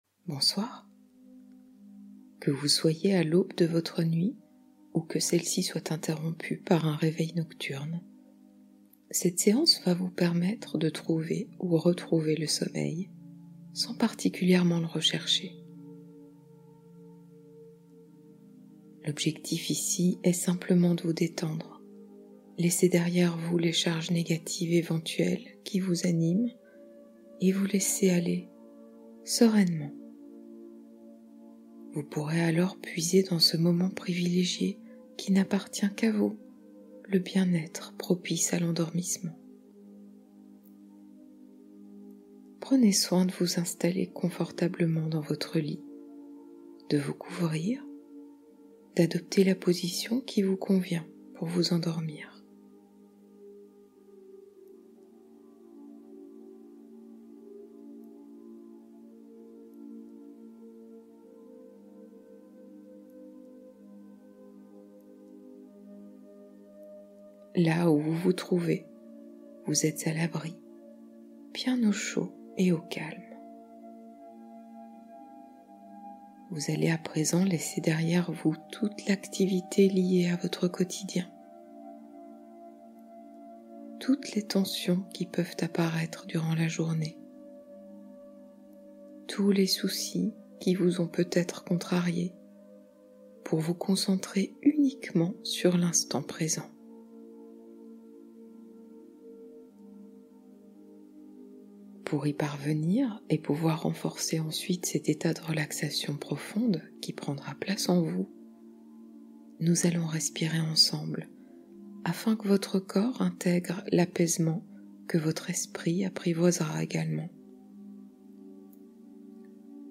Envol vers le sommeil : 1h de détente guidée